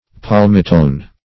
palmitone - definition of palmitone - synonyms, pronunciation, spelling from Free Dictionary Search Result for " palmitone" : The Collaborative International Dictionary of English v.0.48: Palmitone \Pal"mi*tone\, n. (Chem.) The ketone of palmitic acid.